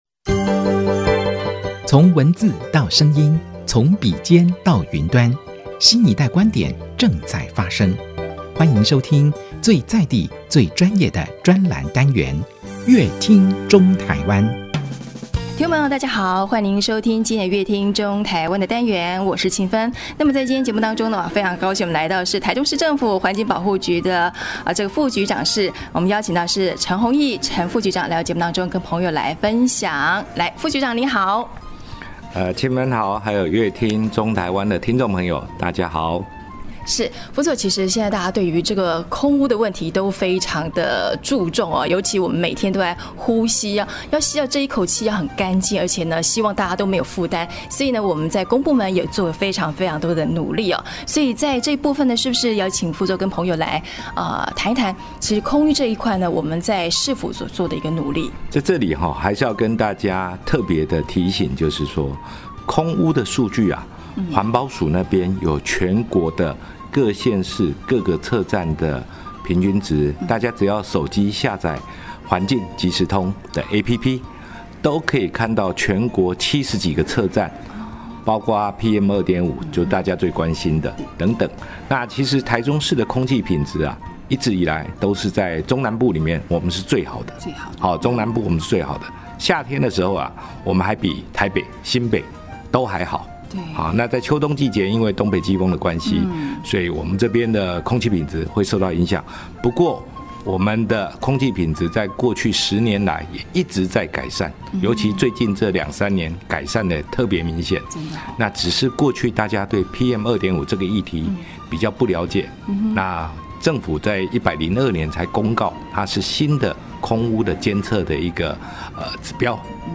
本集來賓：臺中市政府環境保護局陳宏益副局長 本集主題：空污減量 補助汰換誘因與管制並行 本集內容： 每年秋冬季節中南部天空總灰濛濛的，PM2.5的濃度又變高了。